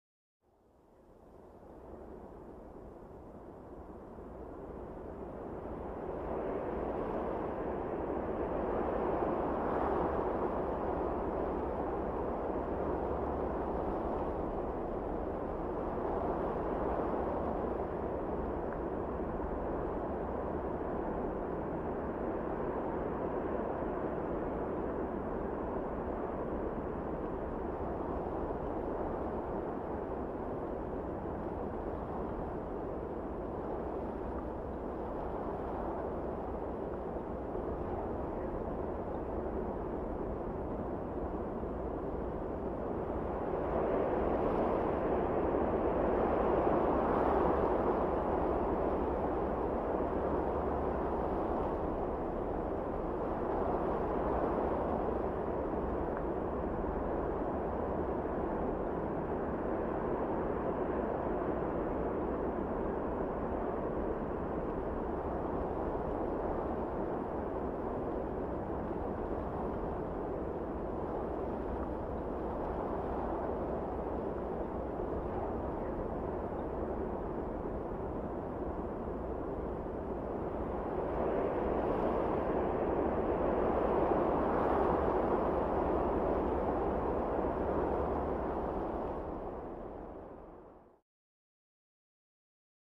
دانلود صدای باد 1 از ساعد نیوز با لینک مستقیم و کیفیت بالا
جلوه های صوتی